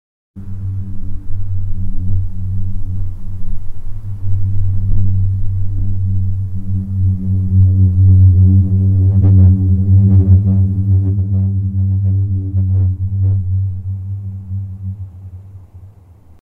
На этой странице собраны разнообразные звуки песка: от шуршания под ногами до шелеста дюн на ветру.
Звук песка пустыни, пение дюн